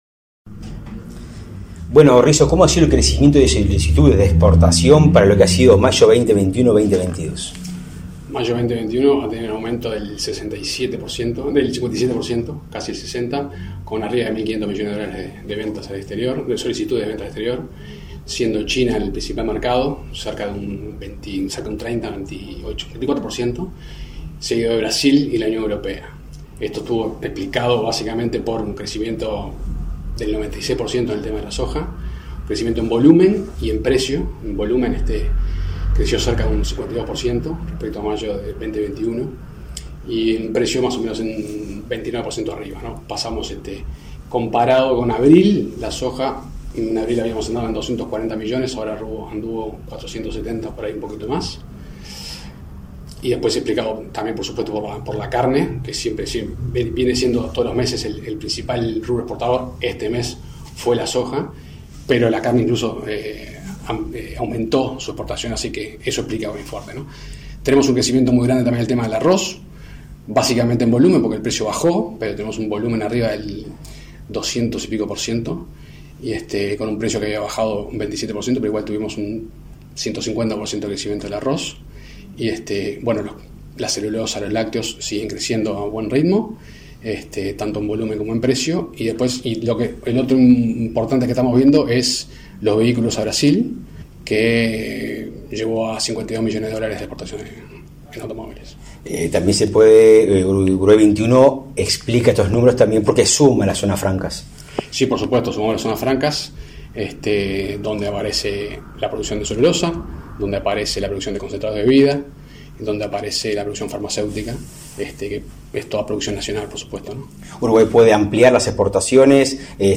Entrevista al director ejecutivo de Uruguay XXI, Sebastián Risso